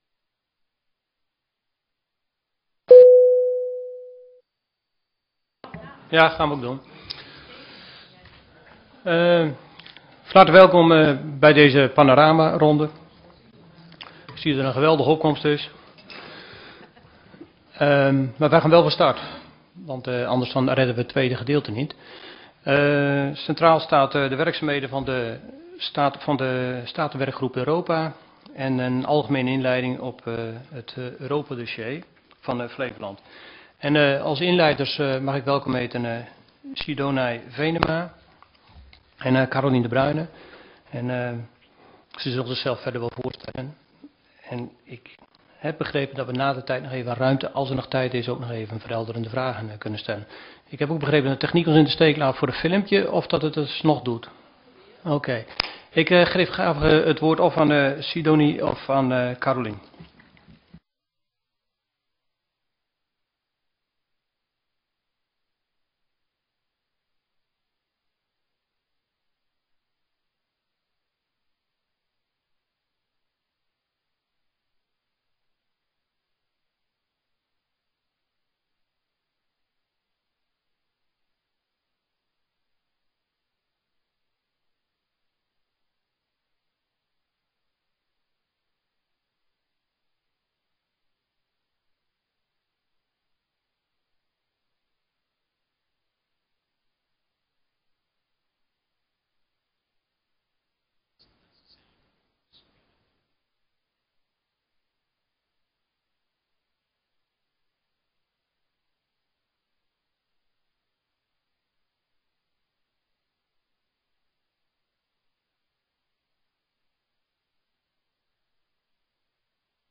Download de volledige audio van deze vergadering
Locatie: Statenzaal